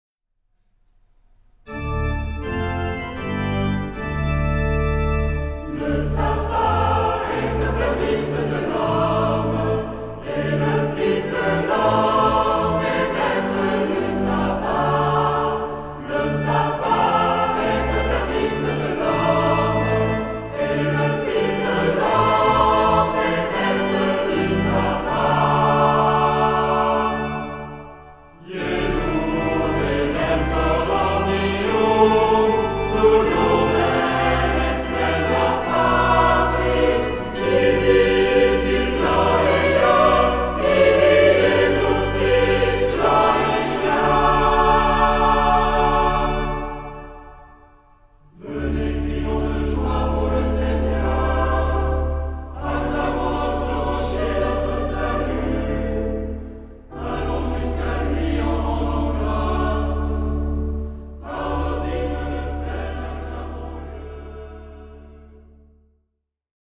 Genre-Stil-Form: Tropar ; Psalmodie
Charakter des Stückes: andächtig
Chorgattung: SATB  (4 gemischter Chor Stimmen )
Instrumente: Orgel (1) ; Melodieinstrument (1)
Tonart(en): D-Dur